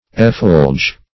Effulge \Ef*fulge"\, v. i.